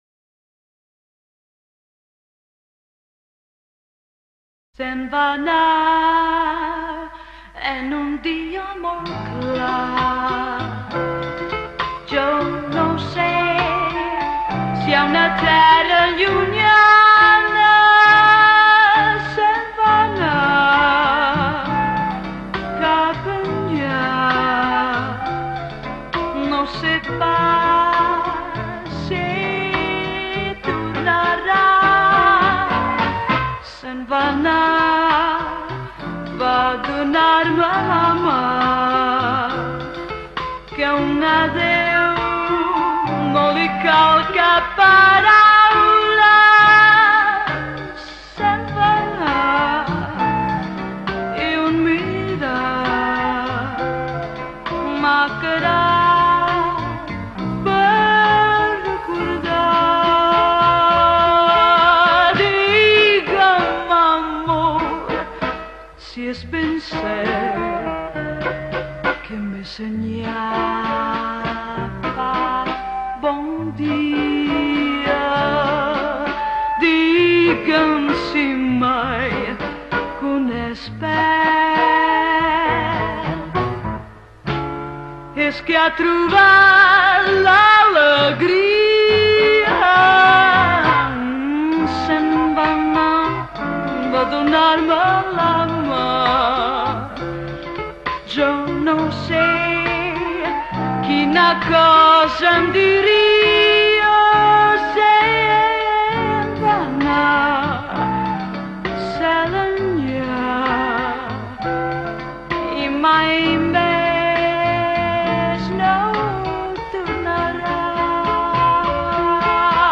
anava una mica passada de decibels